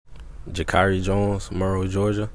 Pronunciations